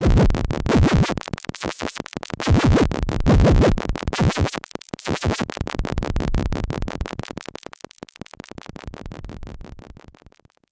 Sci-Fi Ambiences
AFX_ENERGYBEAM_03_DFMG.WAV
Energy Beam 03